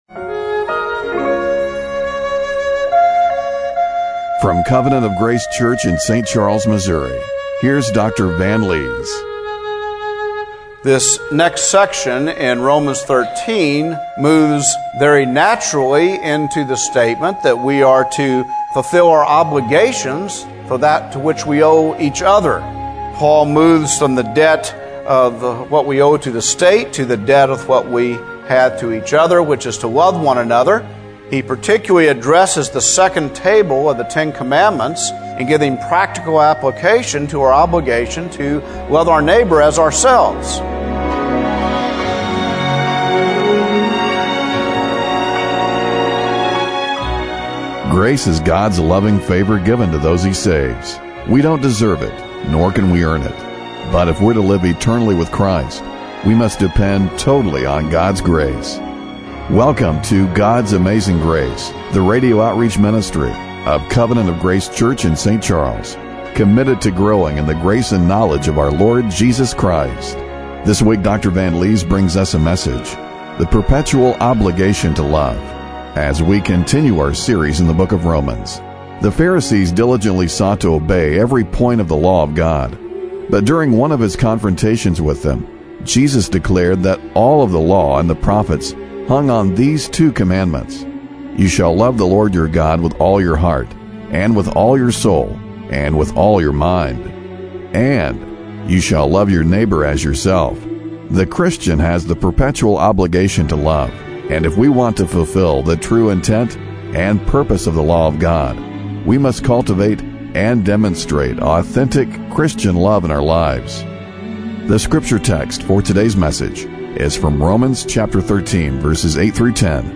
Romans 13:8-10 Service Type: Radio Broadcast How can we cultivate and demonstrate authentic Christian love in our lives?